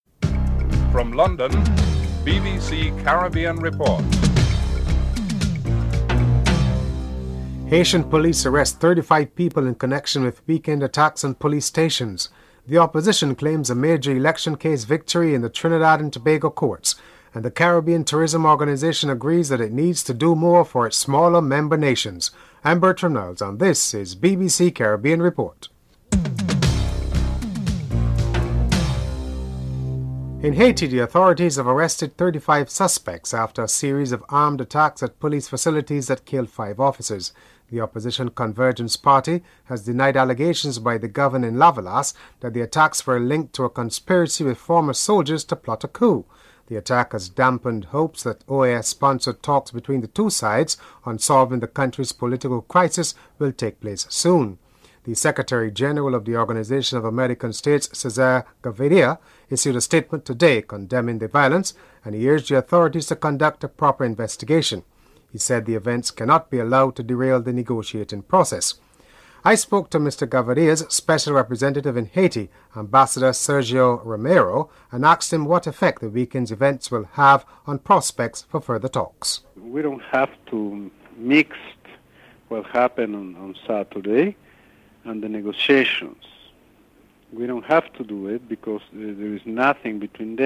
Prime Minister Basdeo Panday is interviewed (06:05-06:56)